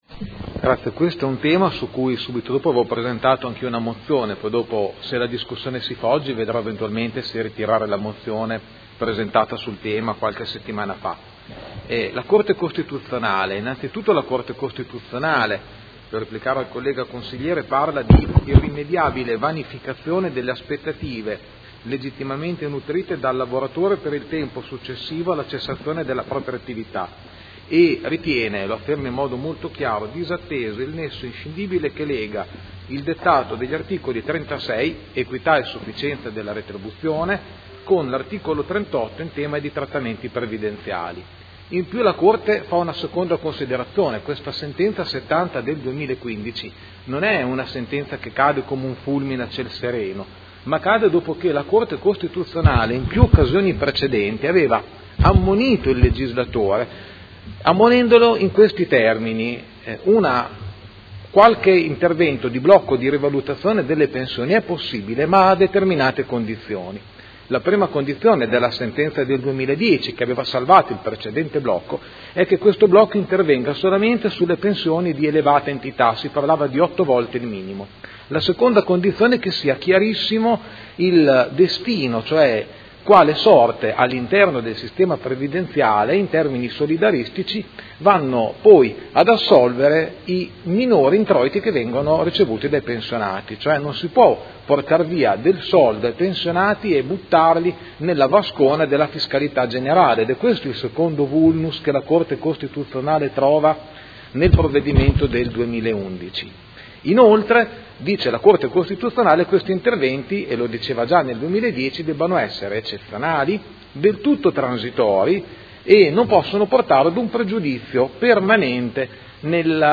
Seduta del 28 gennaio. Ordine del Giorno n° 12761 presentato dal Gruppo Consiliare del PD avente per oggetto: Rivalutare e riformare il sistema pensionistico.... Discussione